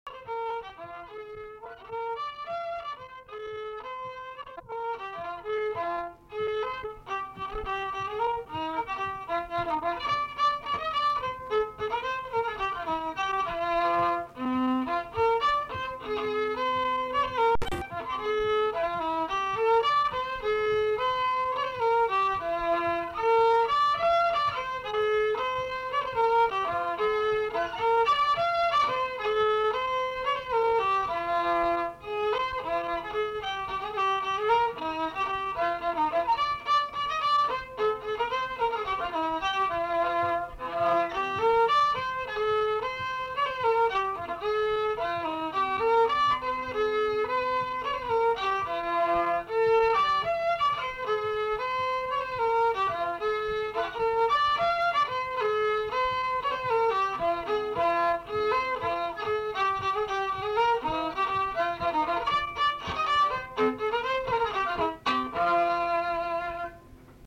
Dalykas, tema šokis
Erdvinė aprėptis Dargužiai Viečiūnai Rietavas
Atlikimo pubūdis instrumentinis
Instrumentas smuikas
Pastabos Pradžioj derina smuiką (įrašyta atskirai nuo kūrinio)